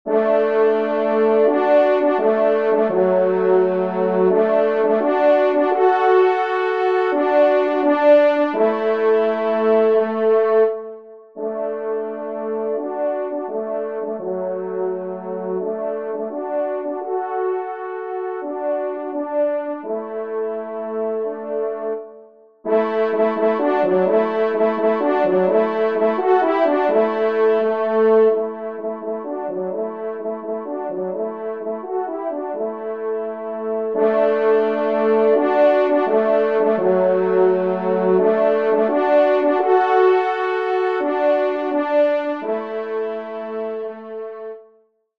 Genre :  Divertissement pour Trompes ou Cors en Ré
2e Trompe